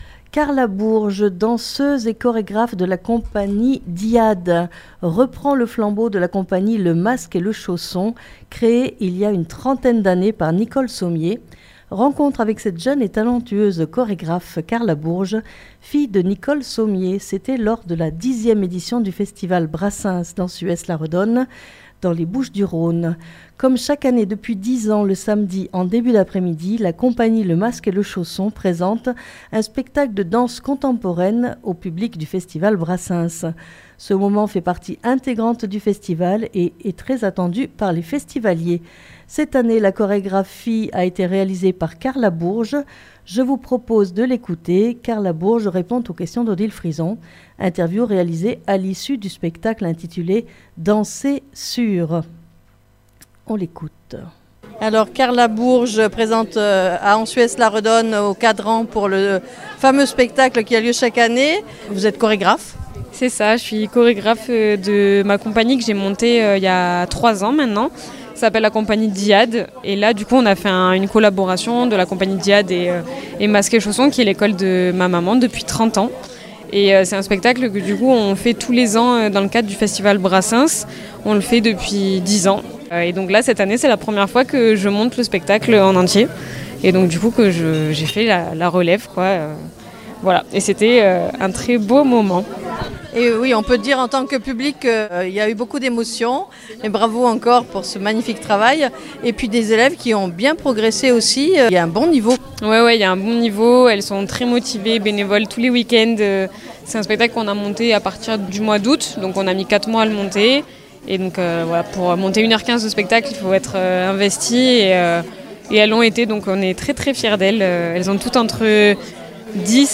Interview réalisée à l'issue du spectacle intitulé "Danser sur..."